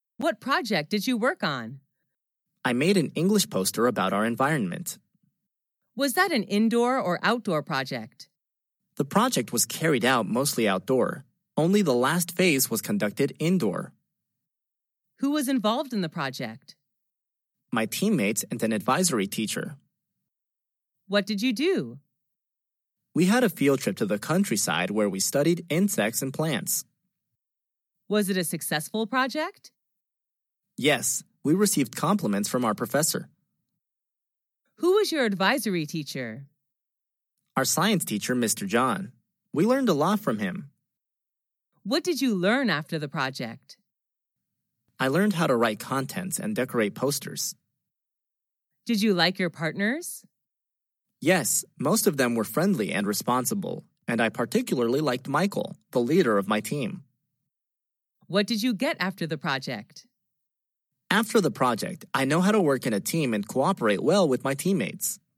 Sách nói | QA-50